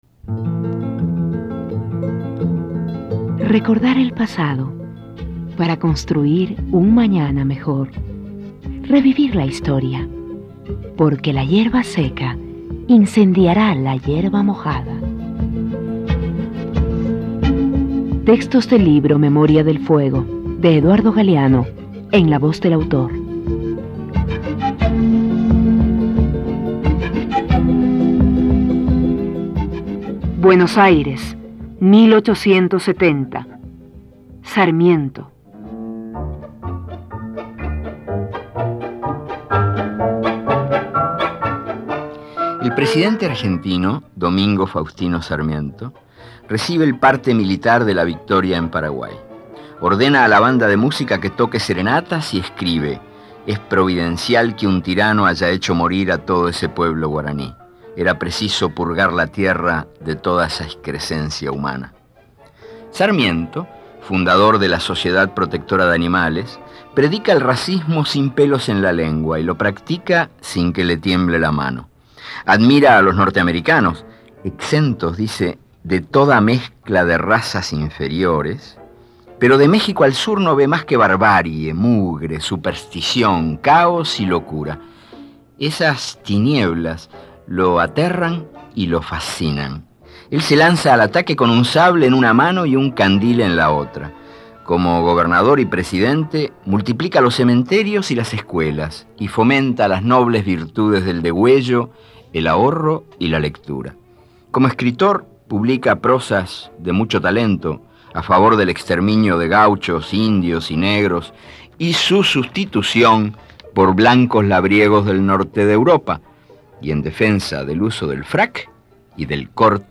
Este archivo de sonido ofrece la lectura del texto en la voz de su autor.